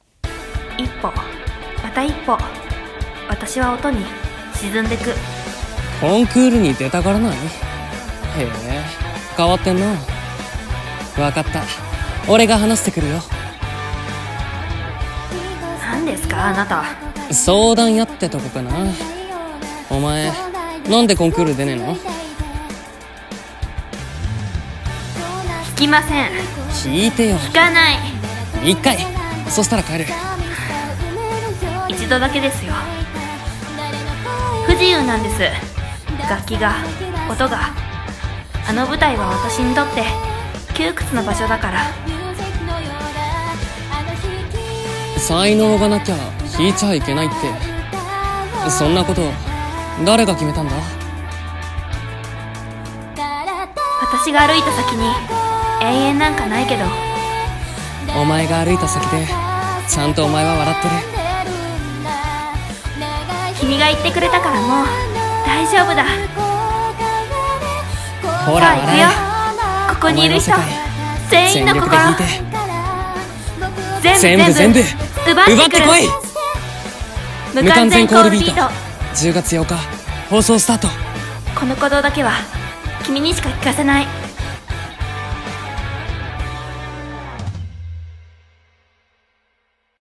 【アニメCM風声劇】